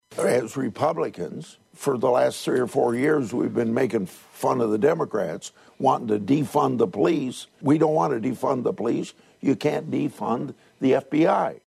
GRASSLEY DISAGREES:
GRASSLEY MADE HIS COMMENTS DURING TAPING OF THE “IOWA PRESS” PROGRAM THAT AIRED ON IOWA P-B-S. ……………..